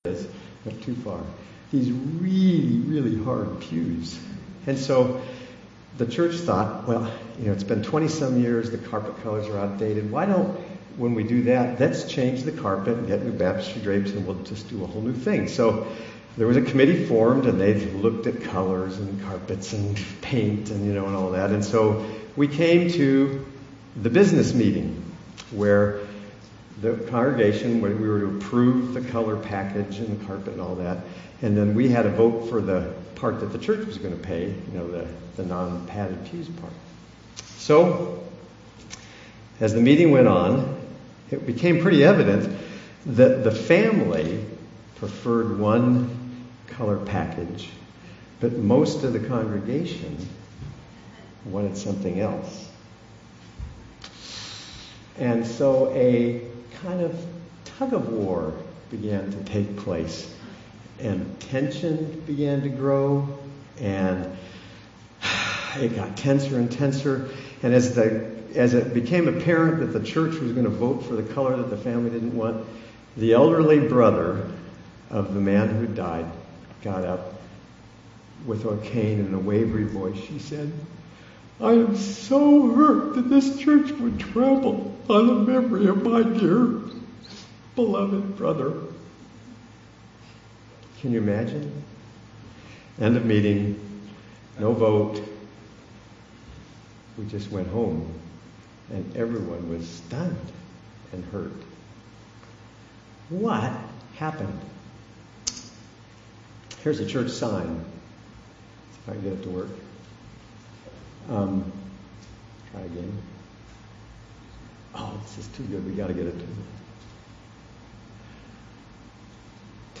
Guest Speakers